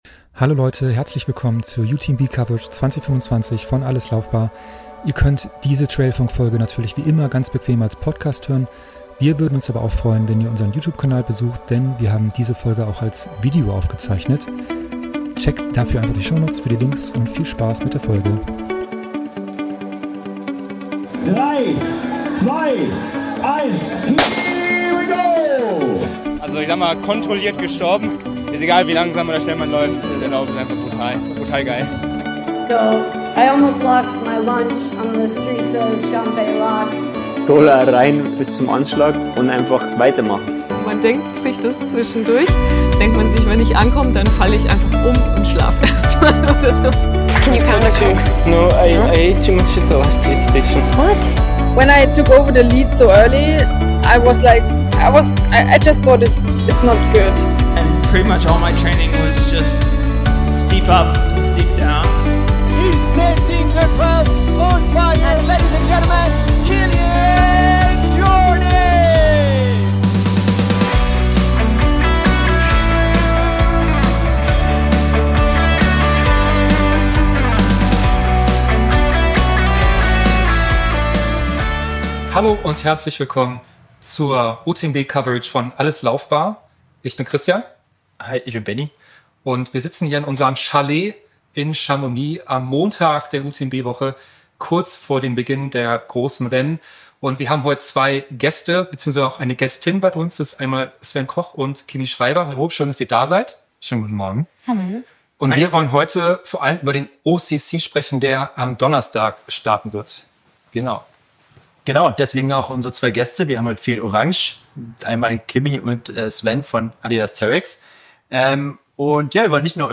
Wir sind in Chamonix und berichten vom UTMB 2025.